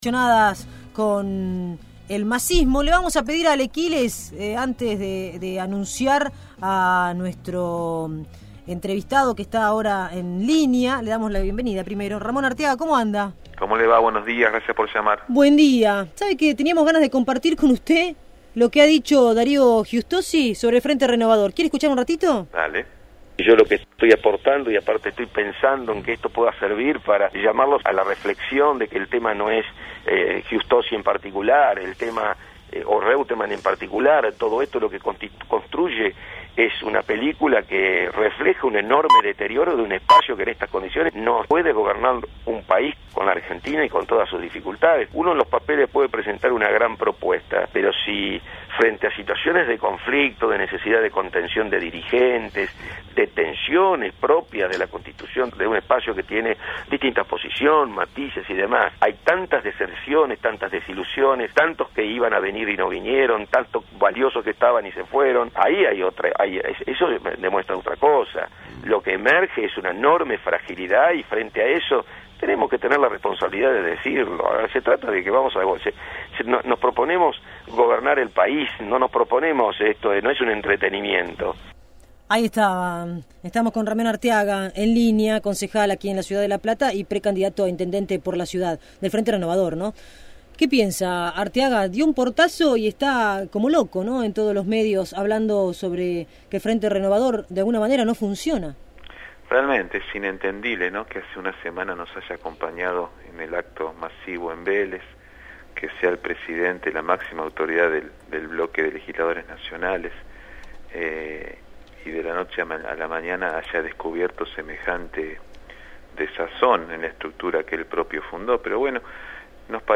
El concejal platense del Frente Renovador y pre-candidato a intendente, José Ramón Arteaga, se refirió en Radiópolis Ciudad Invadida (FM 107.5) a la salida de Darío Giustozzi del espacio liderado por Sergio Massa.